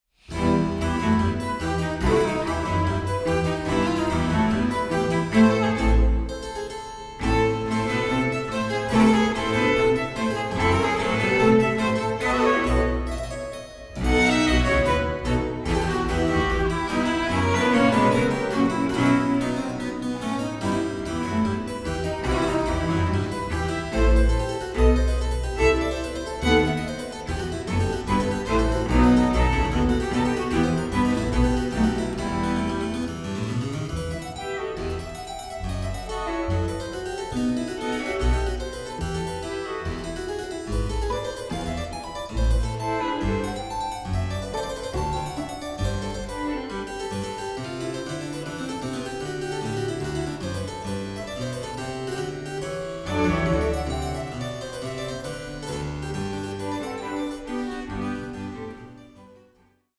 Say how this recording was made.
(48/24, 88/24, 96/24) Stereo 14,99 Select